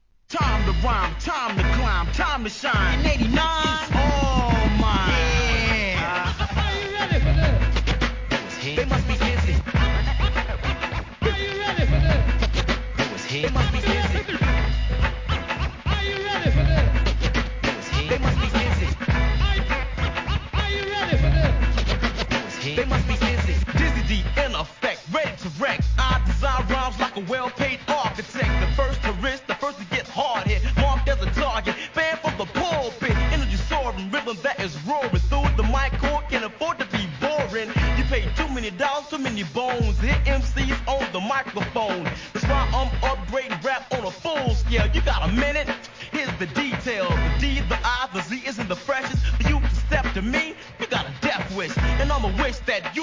HIP HOP/R&B
スリリングなサイレン音が印象的な1990年、知る人ぞ知るミドル！